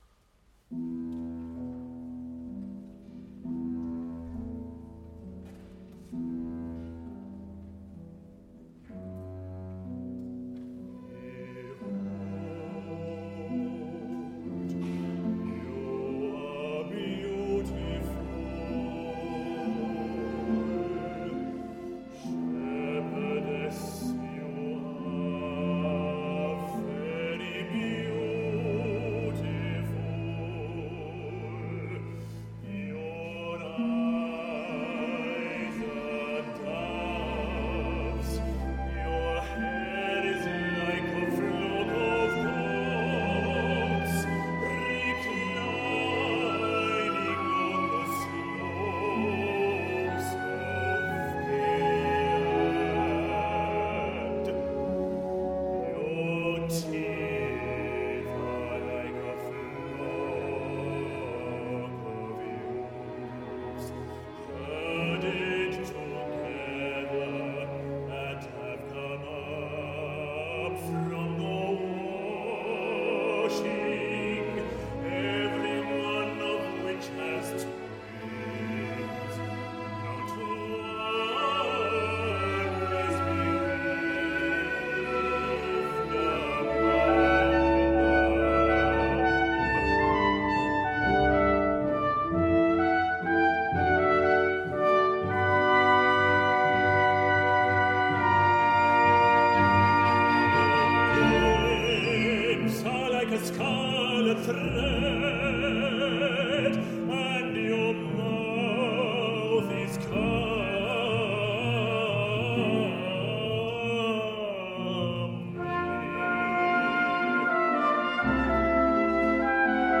Baritone – Live recording at the Vienna Konzerthaus